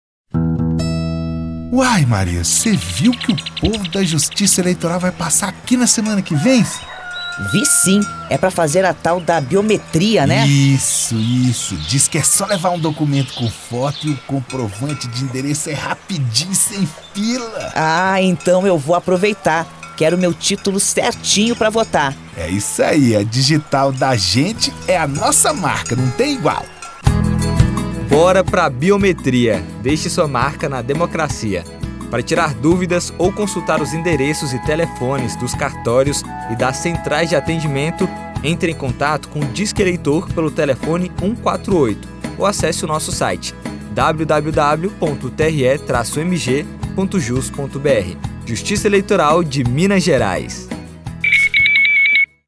TRE-MG DIALOGO ZONA RURAL